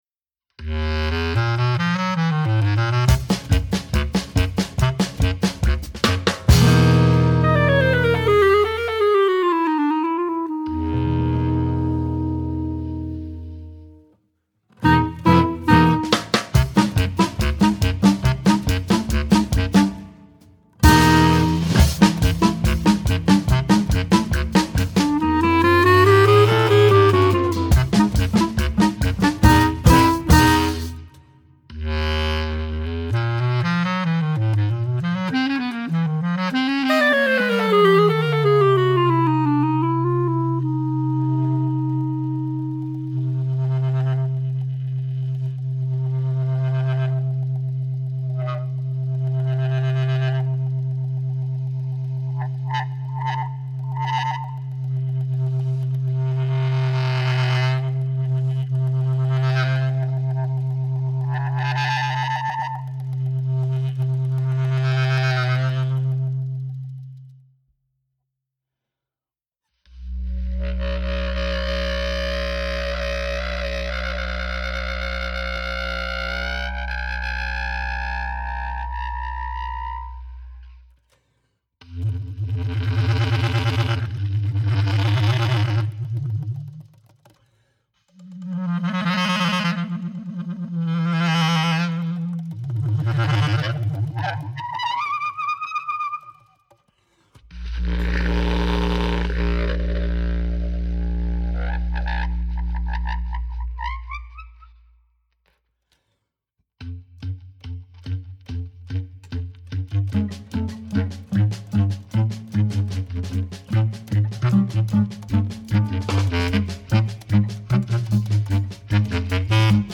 clarinet
bass clarinet
guitar
drums